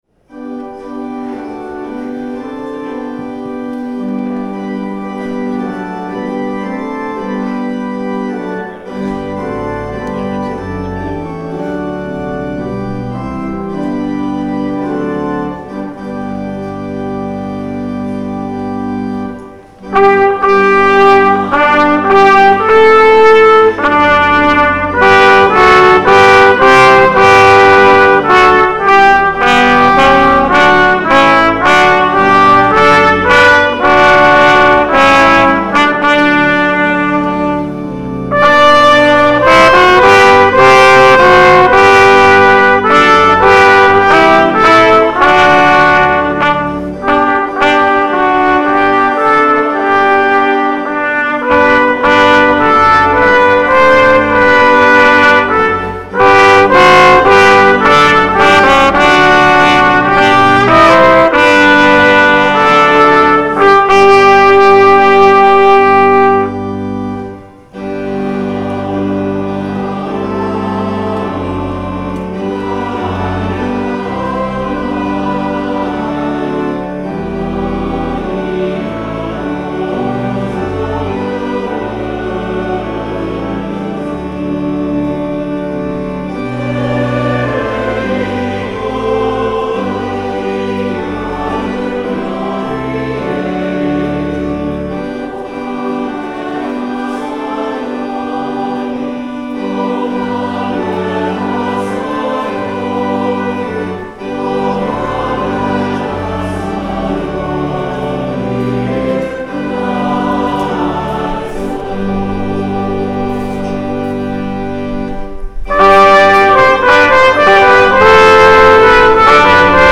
Christmas Eve
LATE CHRISTMAS EVE
(reading in French)
Prayers of the People